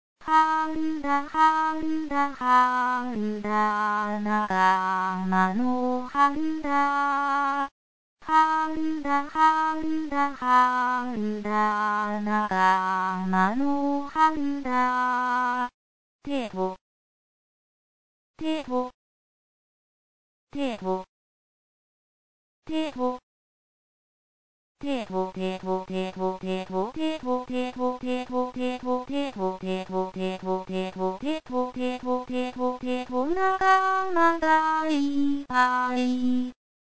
分類アクションソング
アカペラを聴く